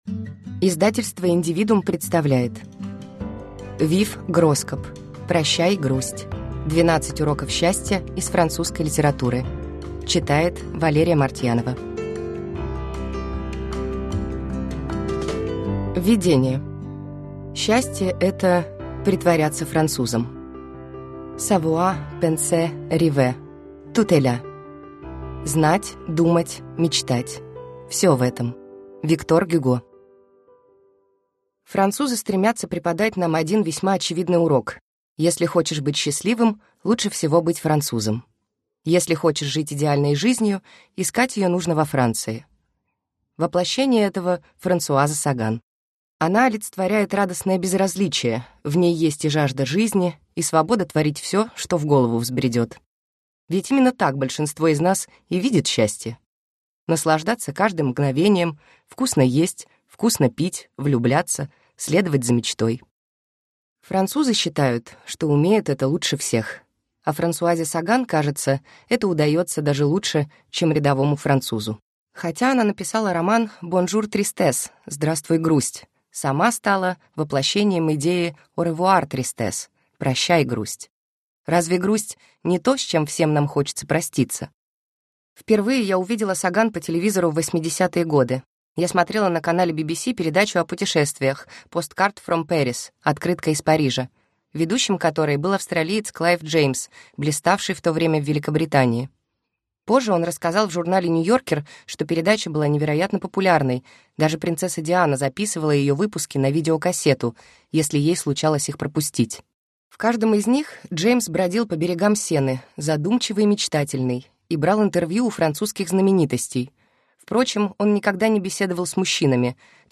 Аудиокнига Прощай, грусть! 12 уроков счастья из французской литературы | Библиотека аудиокниг